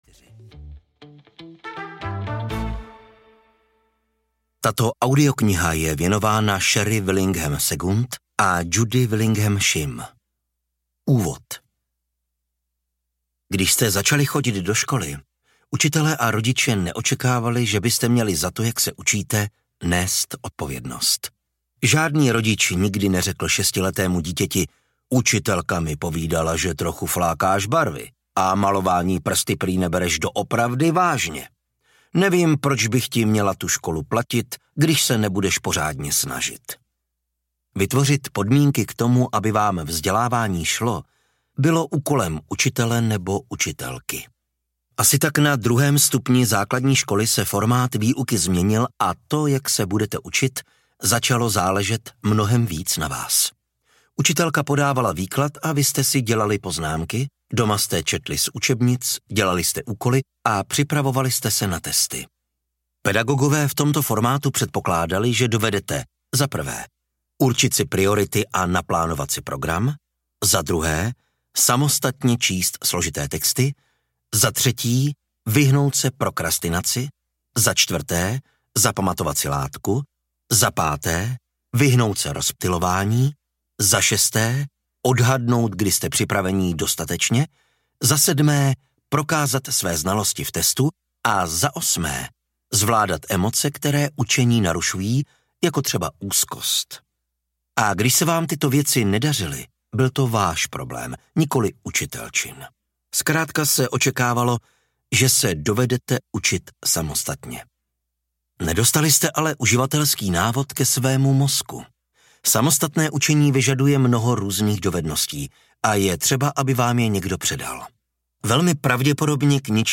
Přechytračte svůj mozek audiokniha
Audiokniha Přechytračte svůj mozek, kterou napsal Daniel T. Willingham.
Ukázka z knihy
prechytracte-svuj-mozek-audiokniha